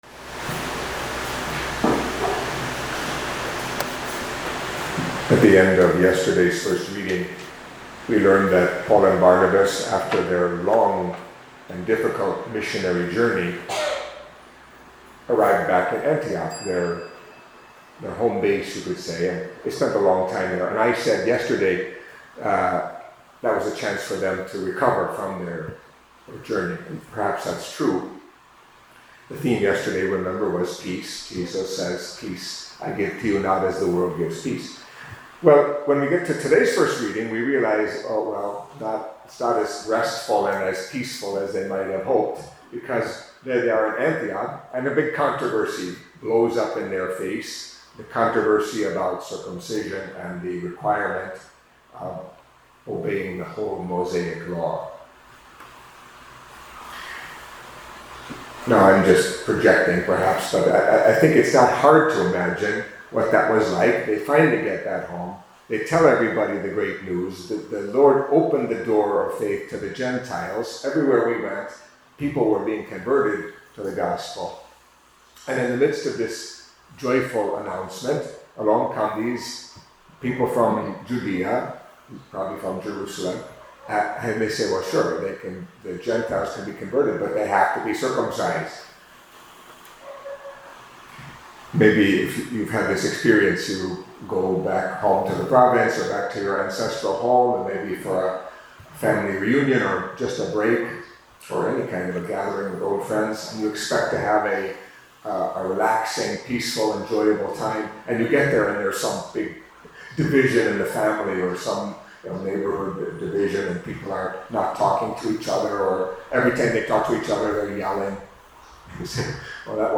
Catholic Mass homily for Wednesday of the Fifth Week of Easter